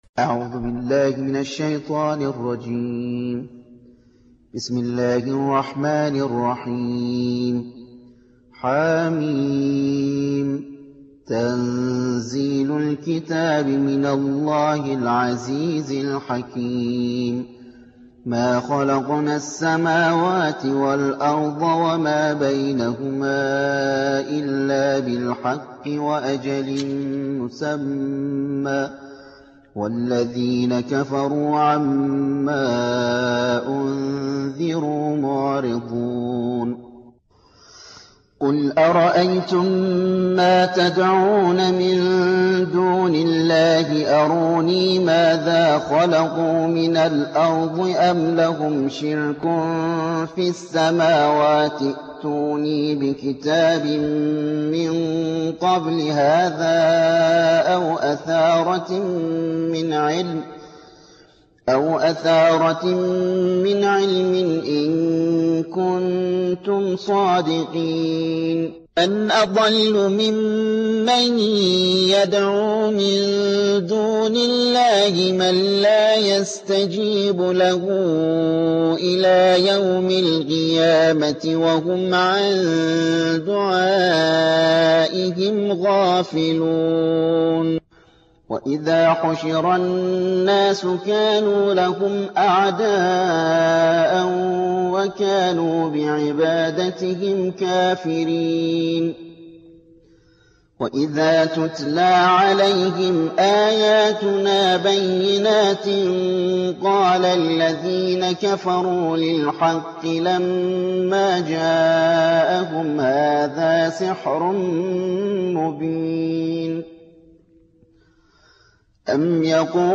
46. سورة الأحقاف / القارئ